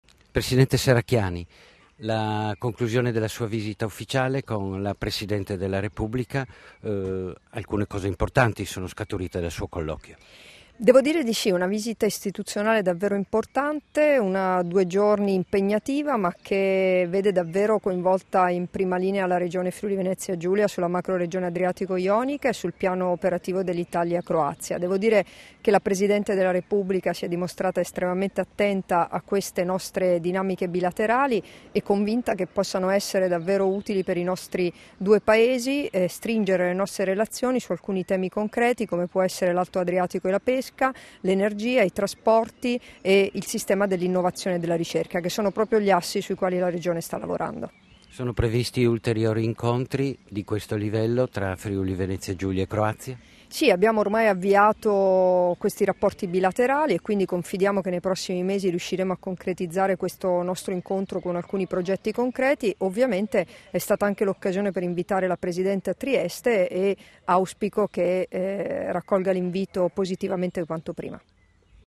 Dichiarazioni di Debora Serracchiani (Formato Mp3) [1150KB]
rilasciate a margine della visita istituzionale in Croazia, a Zagabria il 3 giugno 2015